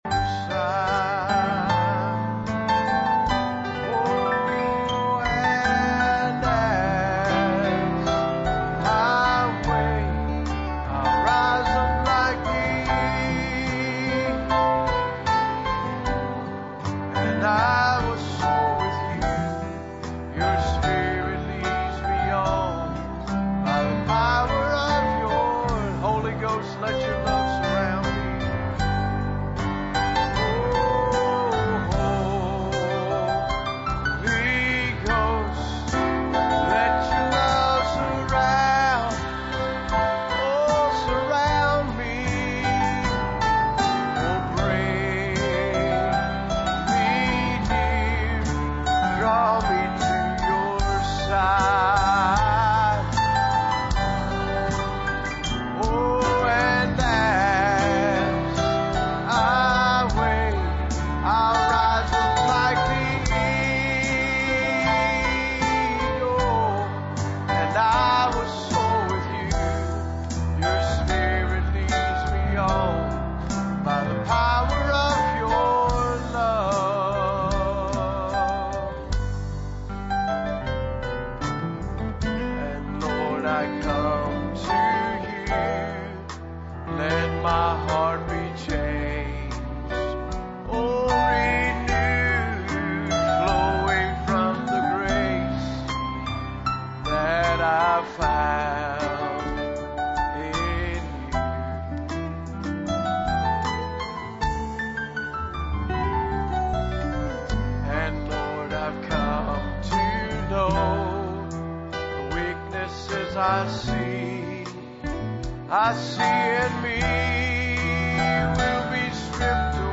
For this or any other sermon on DVD, please contact the library using the contact form on the website.